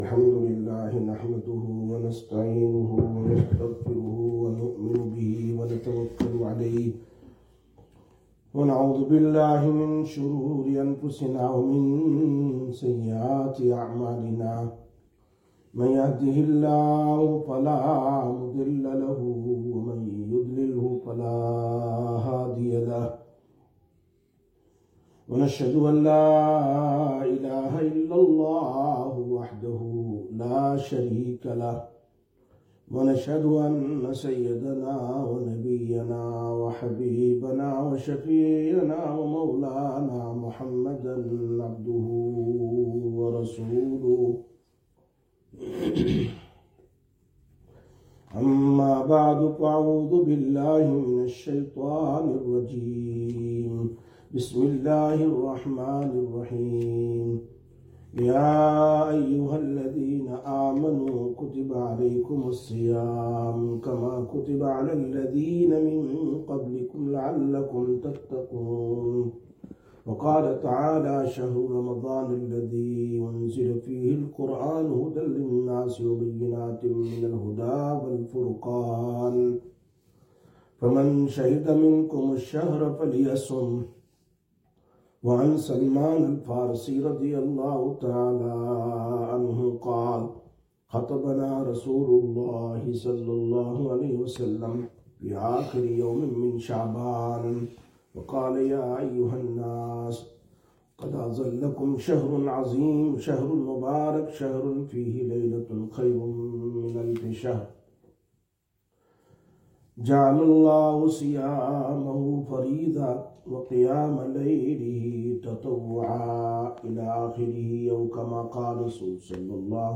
26/02/2025 Sisters Bayan, Masjid Quba